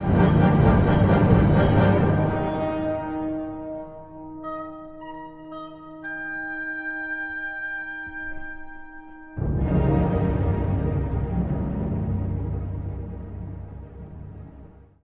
曲が進むにつれて、音楽は次第に熱を帯び、テンポも速度を上げていきます。
2つの主題が絡み合い、転調も起こる中で、骸骨たちの狂騒は最高潮に達します。
突如静寂のなかで、オーボエのソロが響きます。
弦楽器のトリルにより、骸骨たちが慌てて墓へと帰っていきます。